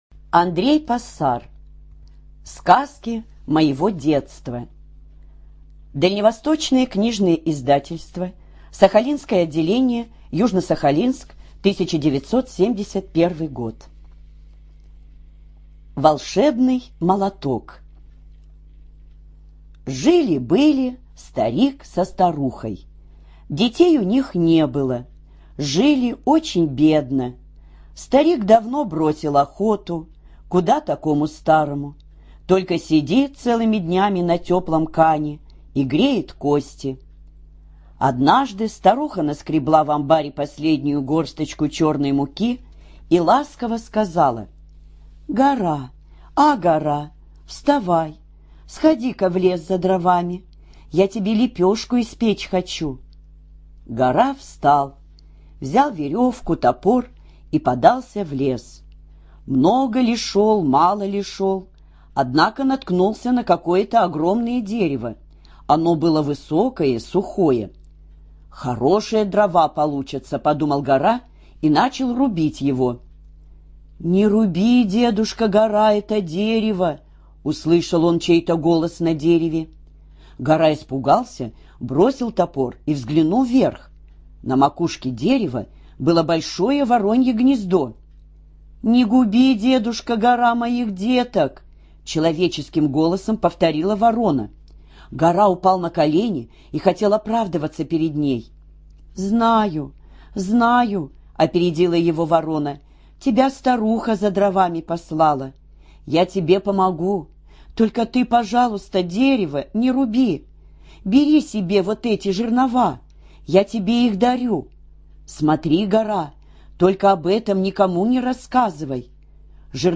ЖанрДетская литература, Сказки
Студия звукозаписиХабаровская краевая библиотека для слепых